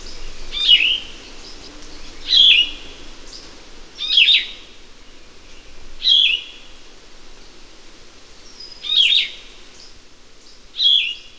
Vireo Goliamarillo,
Yellow-throated Vireo
Vireo flavifrons